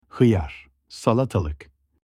cucumber-in-turkish.mp3